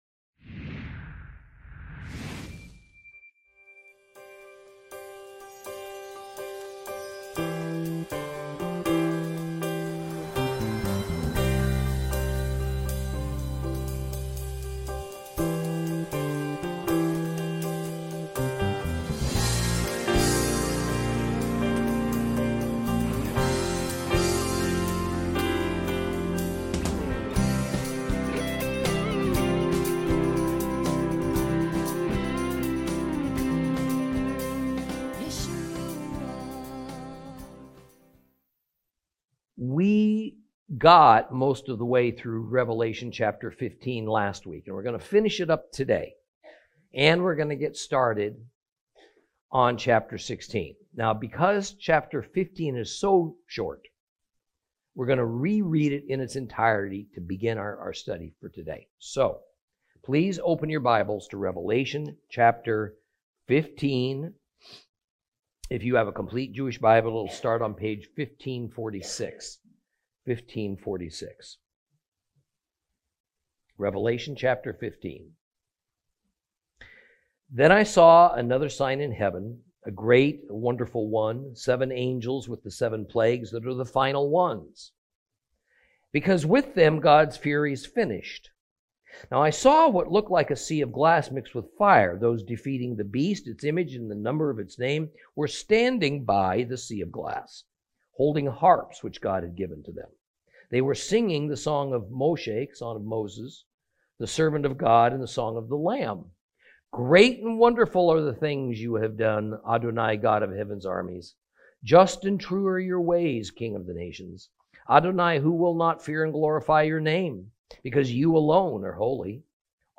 Lesson 33 Ch15 Ch16 - Torah Class